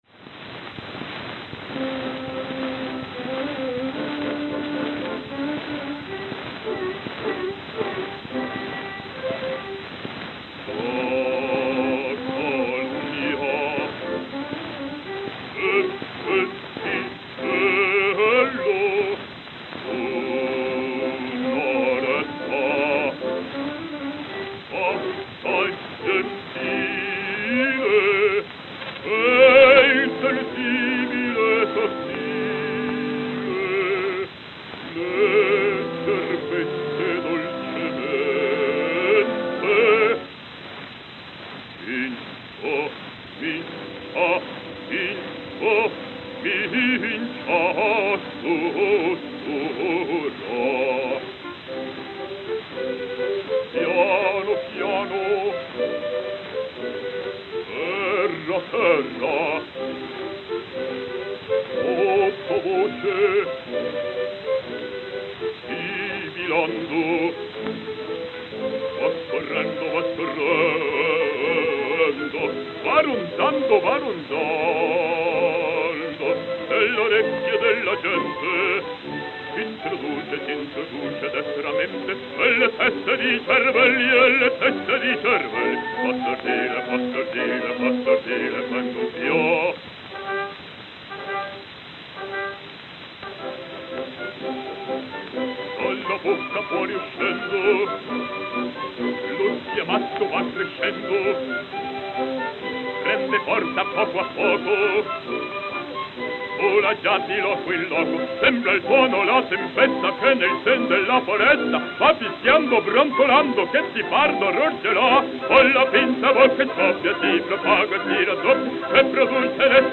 It was a prestigious tier featuring High Classical and Operatic selections with a base price of $2.50. It was analogous to Victor's Red Seal Label and Columbia's Symphony Series.